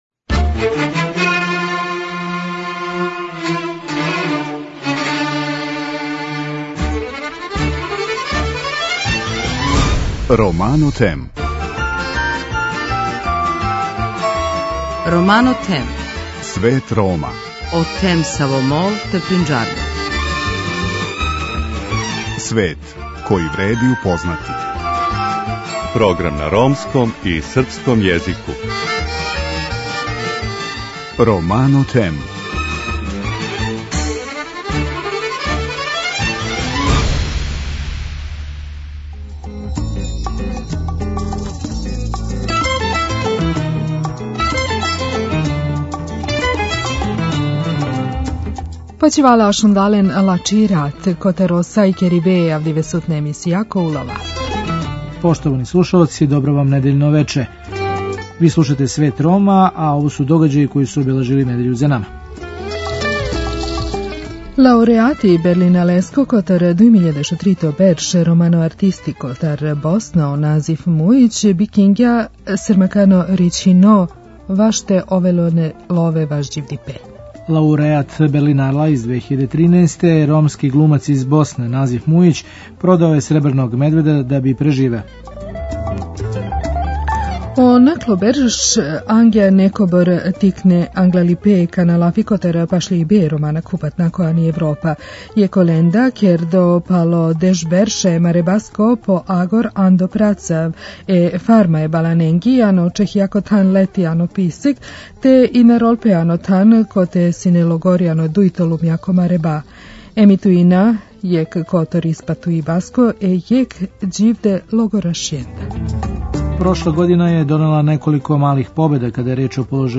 Емитујемо део сведочења једног од преживелих логораша.